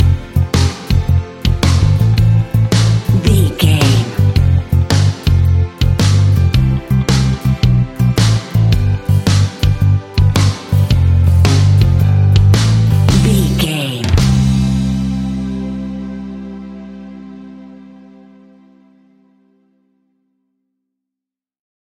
Ionian/Major
melancholic
energetic
smooth
uplifting
electric guitar
bass guitar
drums
pop rock
indie pop
cheesy
organ